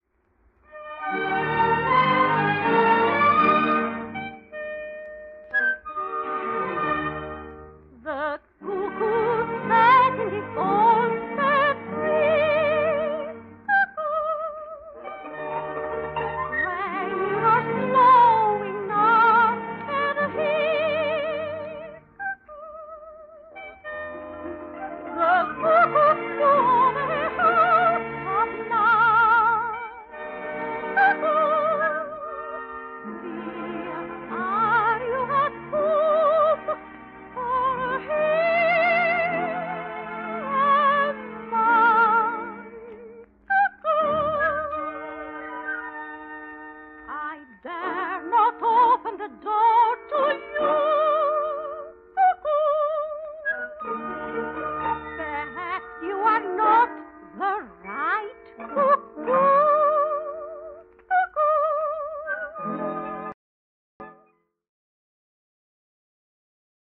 Lyric Soprano